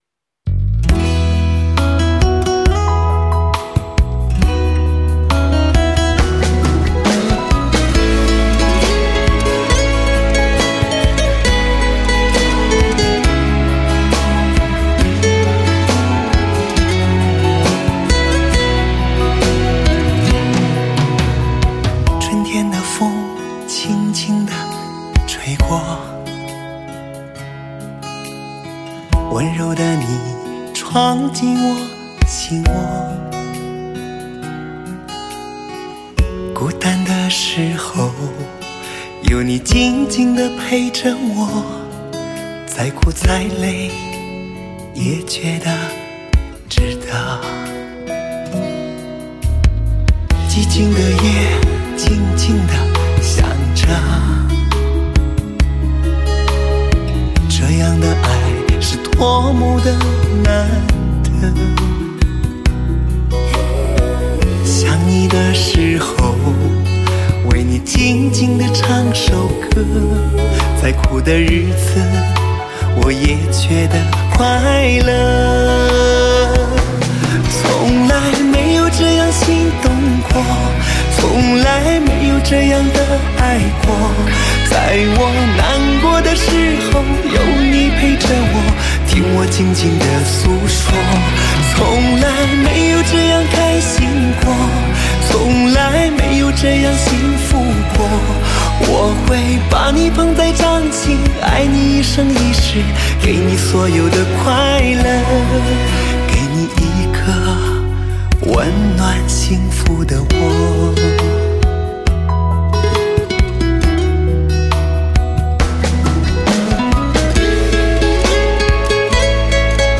魅力男声
极致撩人的唱腔，彰显华语歌坛的最具磁性的男声，每一首都充满了神奇的诱惑魅力，